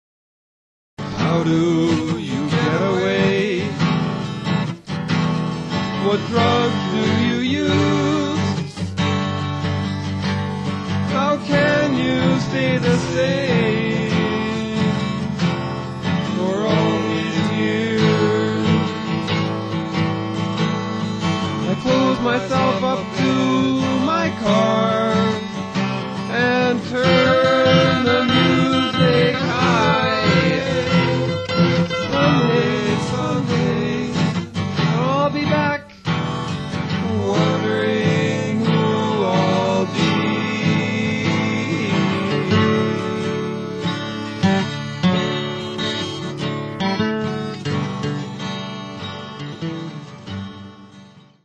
They are highly compressed from the originals, and converted to a suspect, but dead easy format - this is the reality of limited bandwidth.